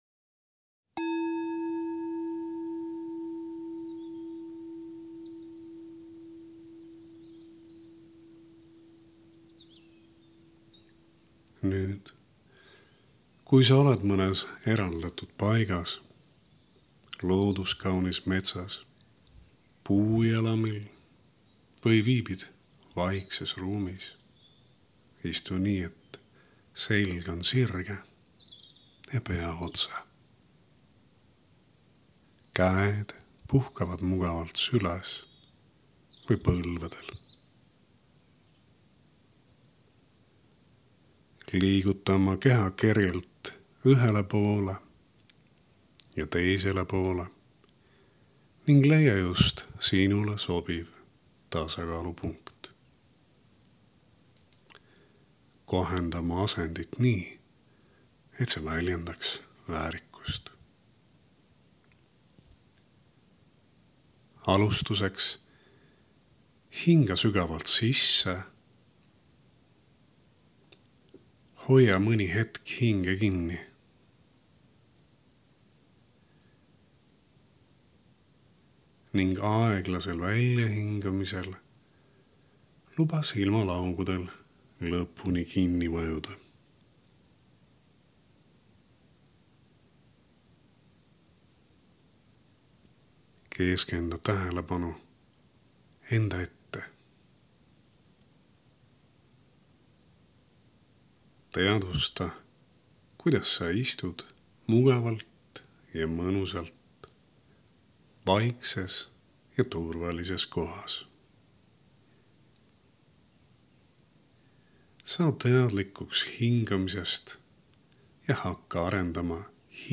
Juhendatud meditatsioonid — Eesti Theravaada Sangha
+Hingamise teadvustamise juhendatud keskendus koos linnulauluga.mp3